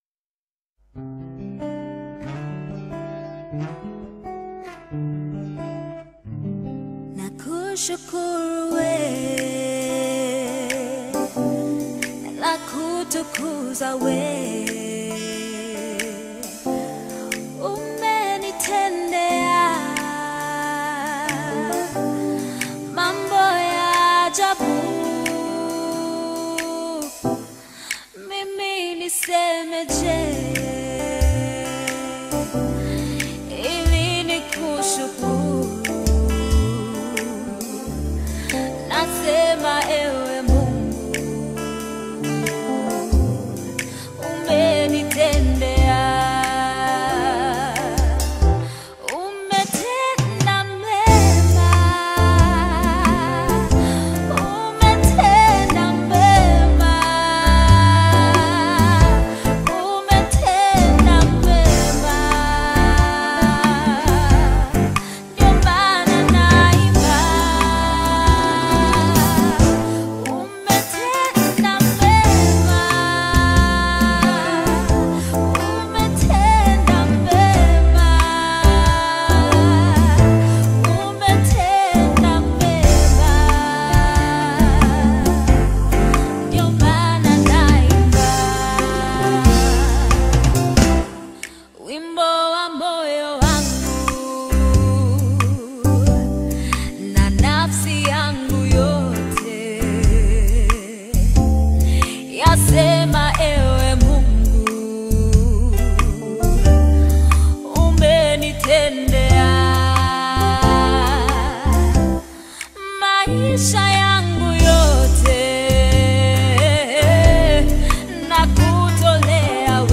February 10, 2025 Publisher 01 Gospel 0